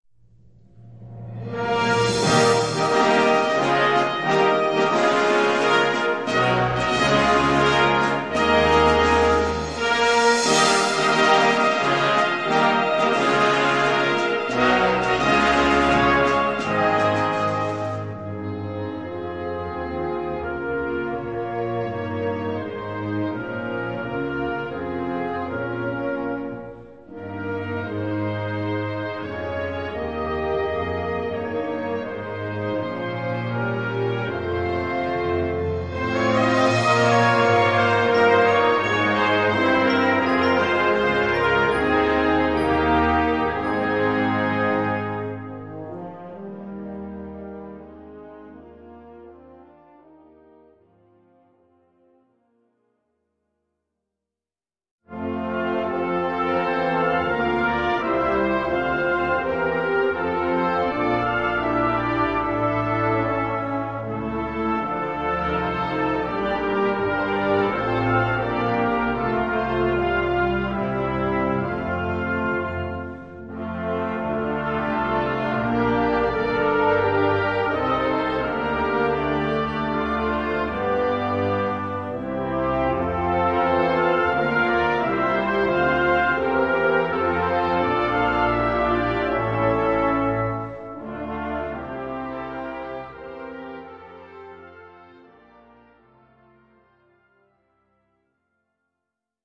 Gattung: Choral
Besetzung: Blasorchester